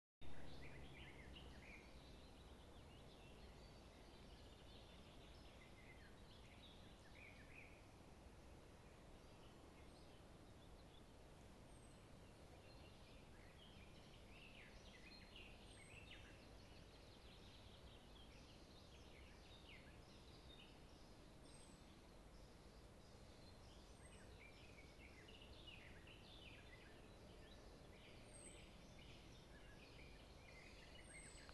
Putns (nenoteikts), Aves sp.
СтатусСлышен голос, крики